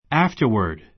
afterward ǽftərwərd ア ふ タワ ド 副詞 あとで; その後で long afterward long afterward （それから）ずっとあとで We studied during the morning and went fishing afterward.